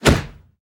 Minecraft Version Minecraft Version latest Latest Release | Latest Snapshot latest / assets / minecraft / sounds / entity / player / attack / knockback4.ogg Compare With Compare With Latest Release | Latest Snapshot
knockback4.ogg